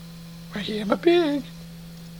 Category: Comedians   Right: Personal
Tags: funny laugh goob voice